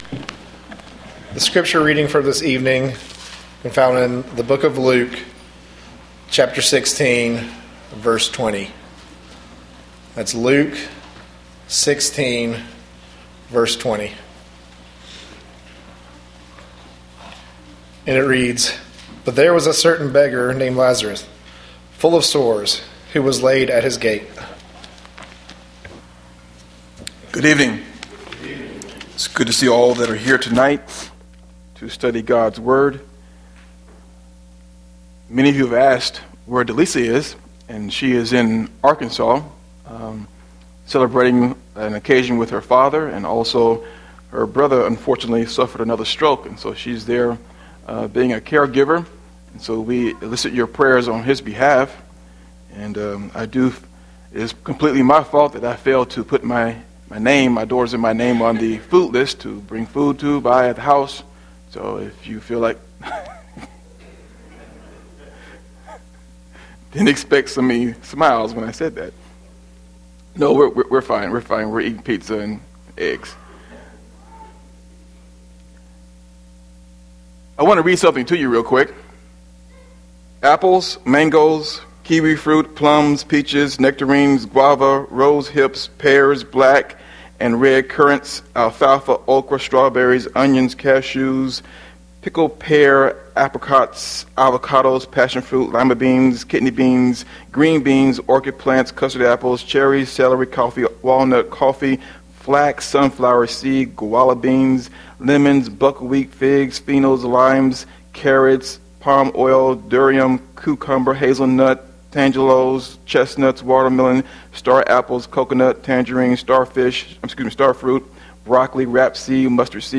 Seminar: What a Christian is to the World